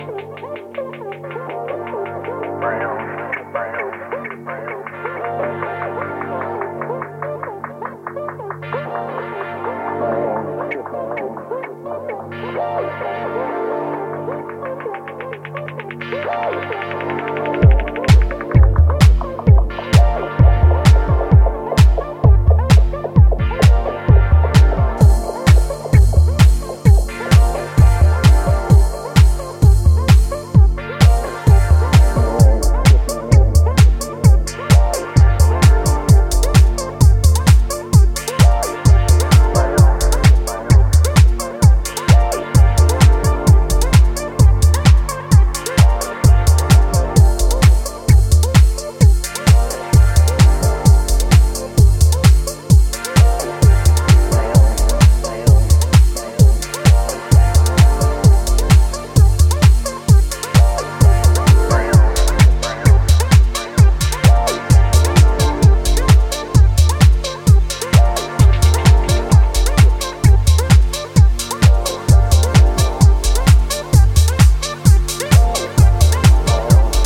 heavy remix